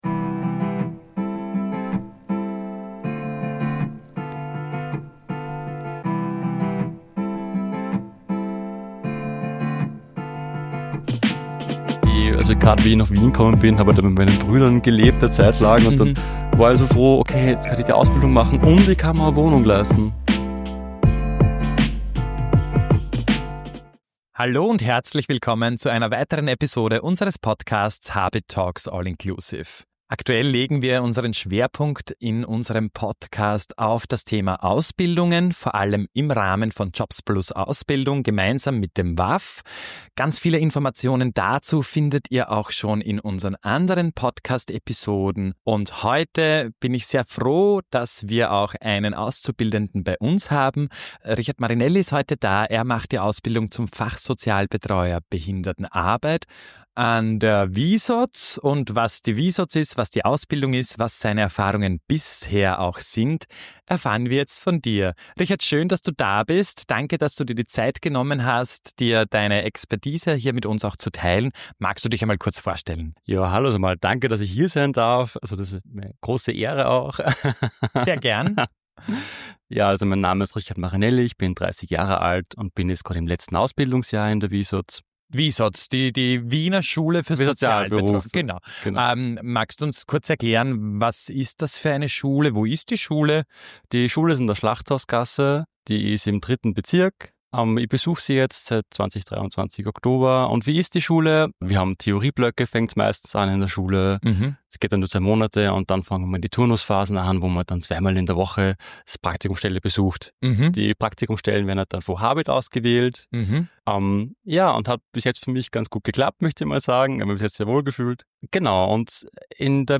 Entlastung durch Ernährung | im Gespräch mit Sasha Walleczek – HABIT Talks: All Inclusive – Lyssna här